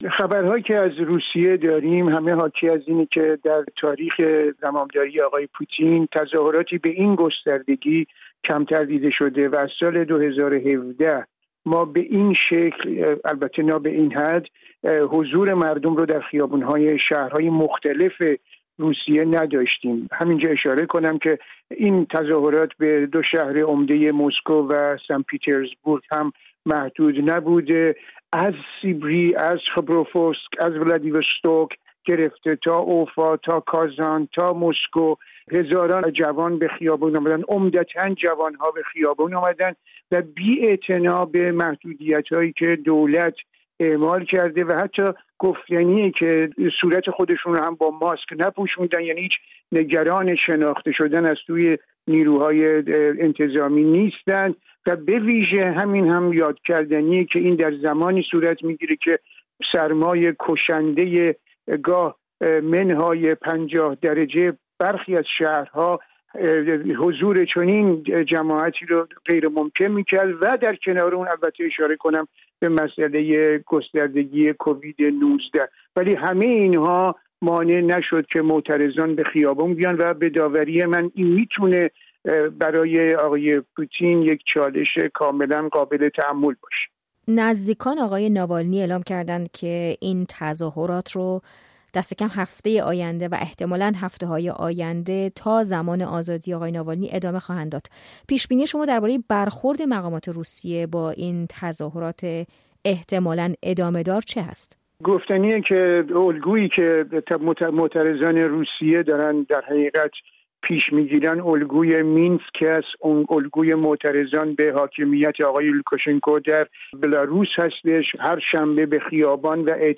با وجود هشدار پلس روسیه به برخورد شدید با تظاهرات حامیان الکسی ناوالنی، از منتقدان سرسخت ولادیمیر پوتین، باز هم این تظاهرات روز شنبه برای آزادی آقای ناوالنی در شهرهای مختلف روسیه شکل گرفته و منجر به بازداشت بیش از دو هزار تن شده است. گفت‌وگوی